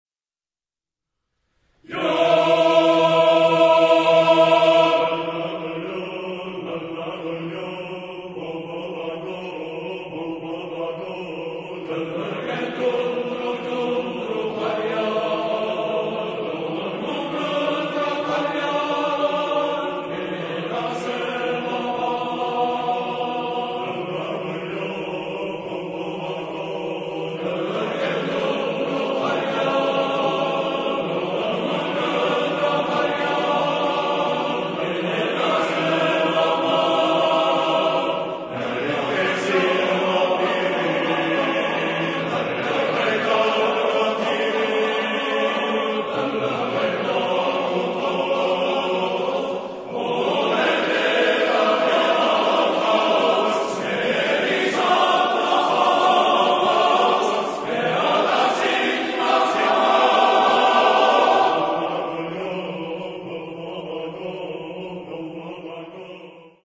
Genre-Style-Forme : Profane ; Pièce vocale ; Traditionnel
Type de choeur : TTBB  (4 voix égales d'hommes )
Tonalité : mi mode de ré